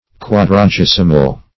Search Result for " quadragesimal" : The Collaborative International Dictionary of English v.0.48: Quadragesimal \Quad`ra*ges"i*mal\, a. [Cf. F. quadrag['e]simal.]
quadragesimal.mp3